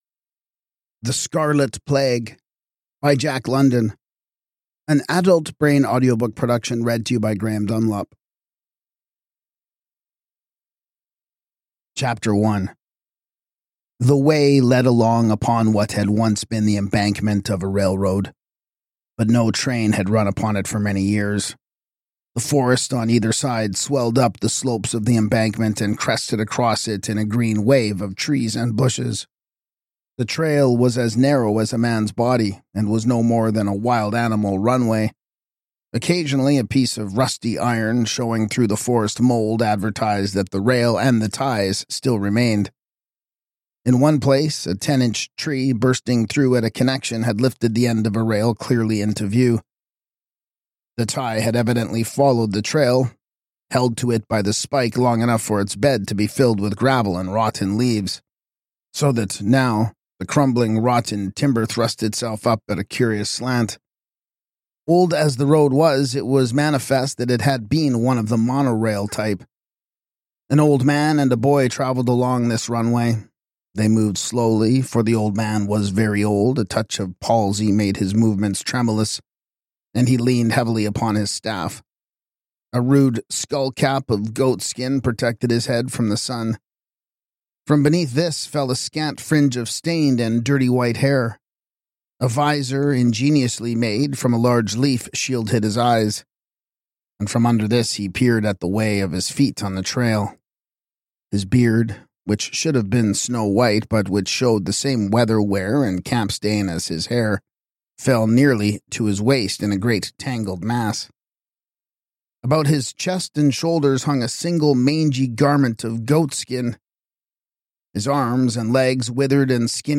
Podcast (audiobooks): Play in new window | Download